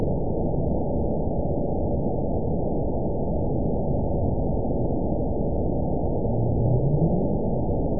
event 920610 date 04/01/24 time 01:12:22 GMT (1 year, 1 month ago) score 9.35 location TSS-AB07 detected by nrw target species NRW annotations +NRW Spectrogram: Frequency (kHz) vs. Time (s) audio not available .wav